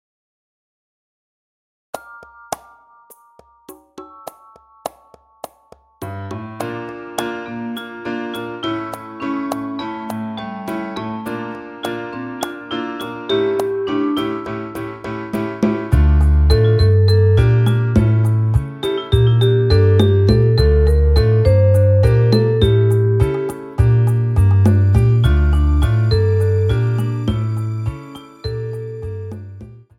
トロンボーン+ピアノ